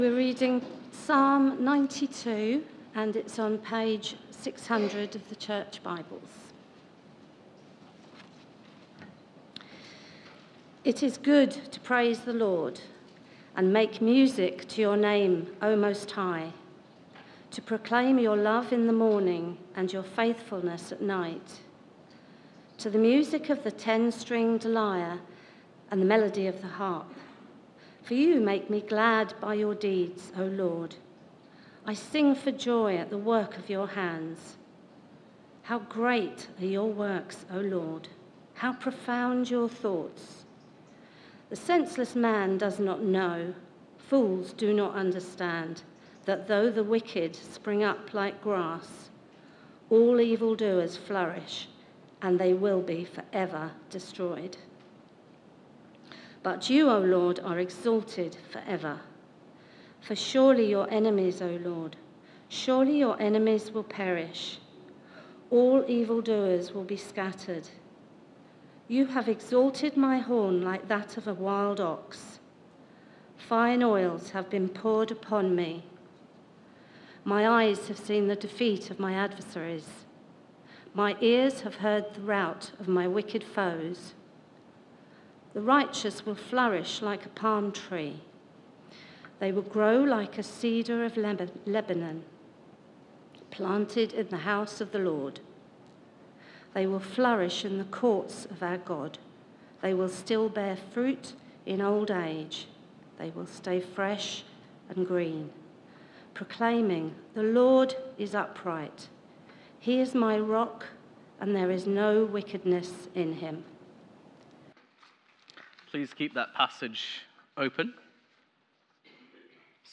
Media for Sunday Service on Sun 01st Sep 2024 10:00
Theme: Sermon